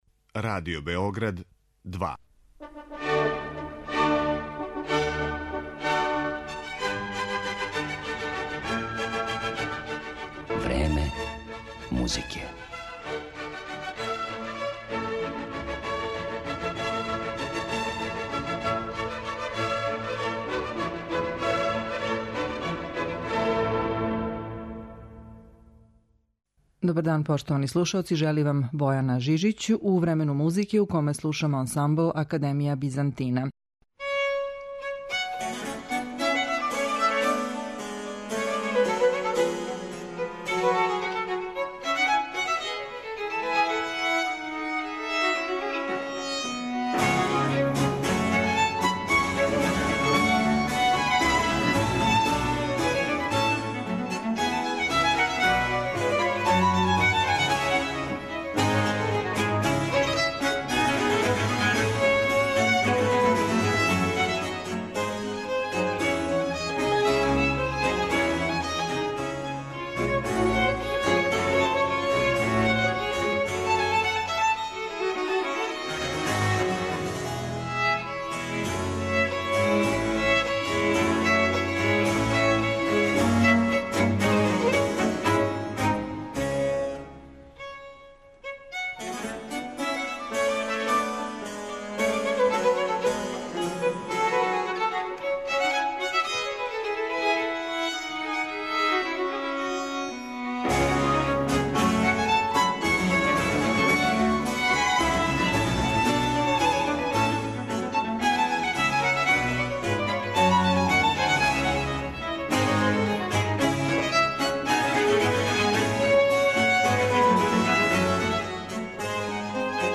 чембалиста и оргуљаш